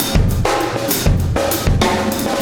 Extra Terrestrial Beat 17.wav